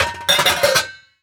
metal_lid_movement_impact_11.wav